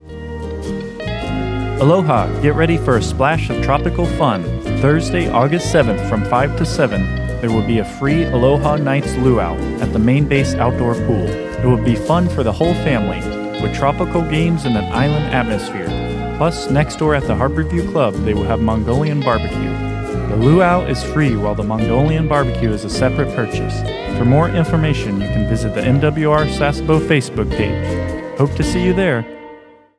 A radio promotion for the MWR Aquatics "Aloha Nights" family event occurring Aug. 7, 2025, on Commander, Fleet Activities Sasebo.